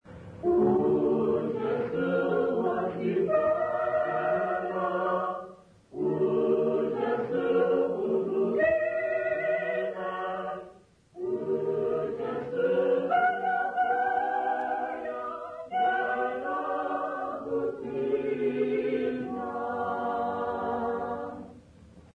Intshanga church music workshop participants
Folk music South Africa
Hymns, Zulu South Africa
Africa South Africa Intshanga, KwaZulu-Natal sa
field recordings
Unaccompanied church hymn.